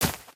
Divergent / mods / Footsies / gamedata / sounds / material / human / step / t_grass1.ogg
t_grass1.ogg